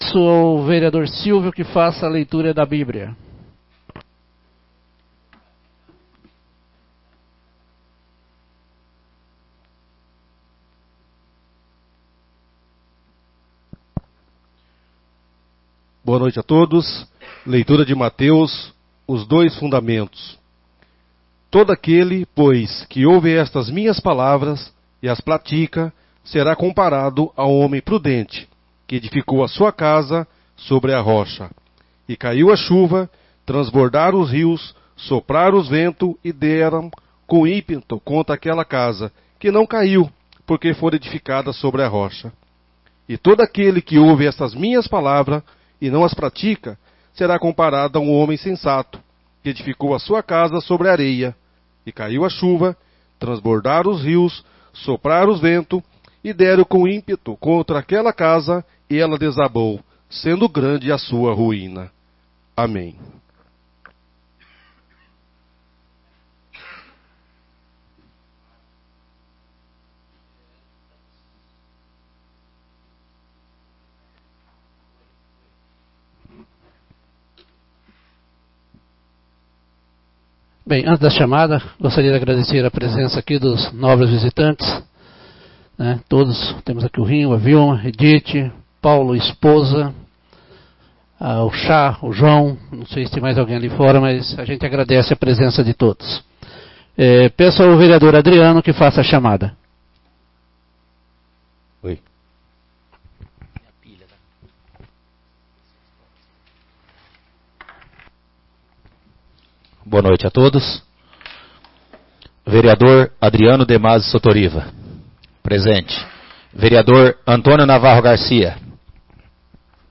Áudio da Sessão Ordinária 56/2019.
Sessão Ordinária do dia 20 de maio de 2019.